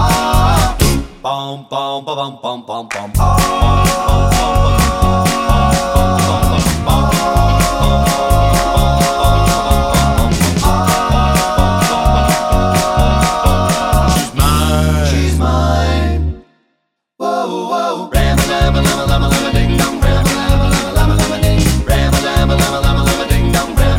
No Saxophone Rock 'n' Roll 2:17 Buy £1.50